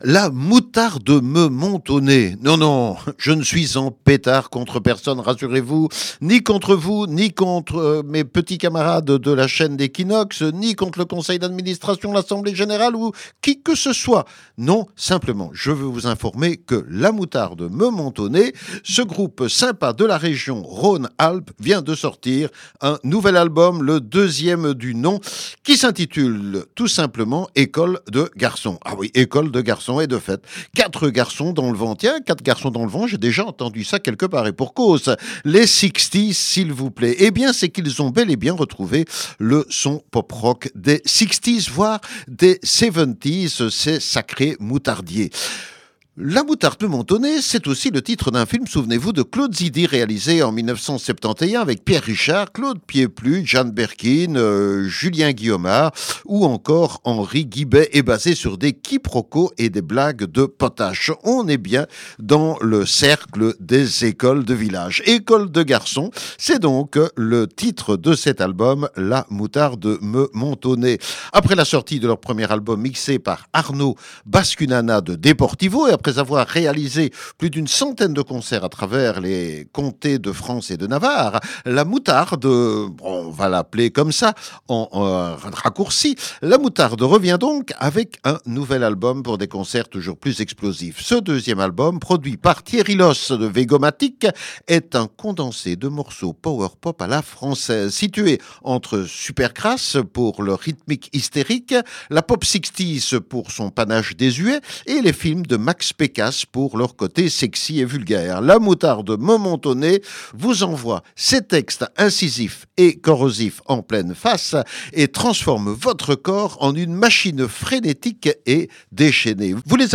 est un condensé de morceaux power-pop à la française.